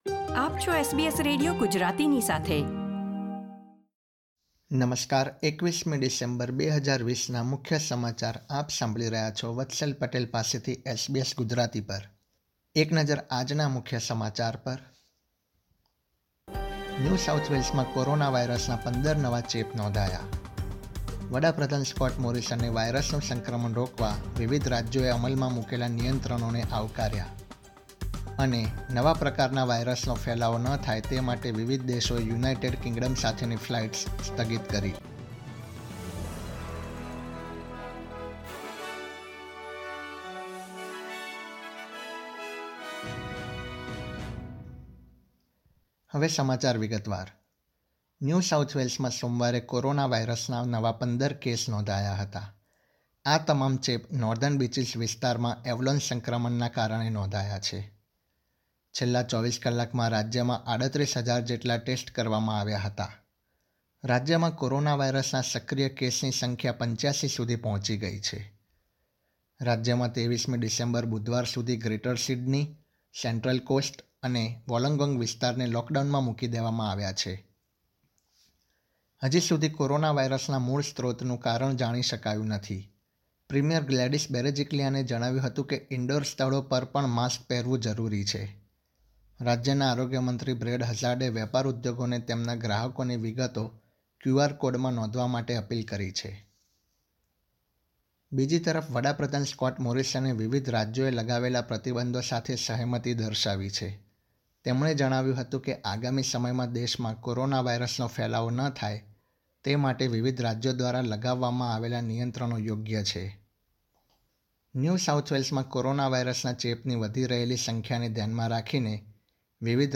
SBS Gujarati News Bulletin 21 December 2020
gujarati_2112_newsbulletin.mp3